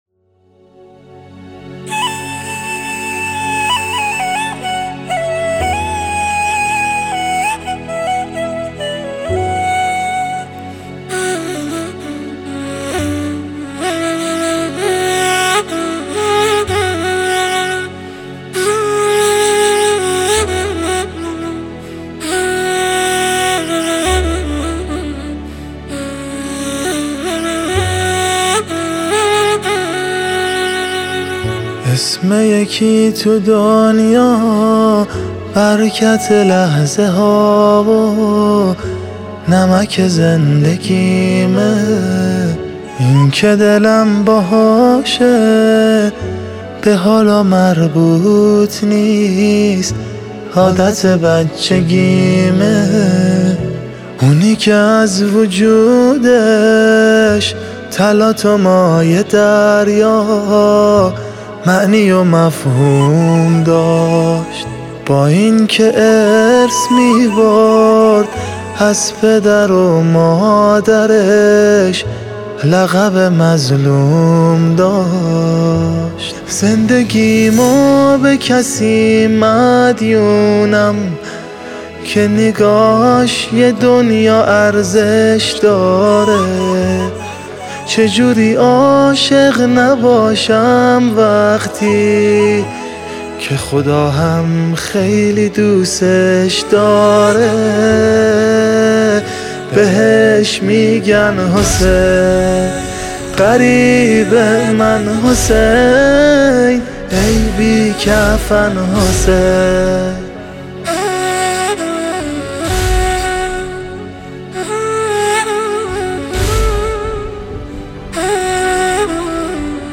قطعه‌ای عاشورایی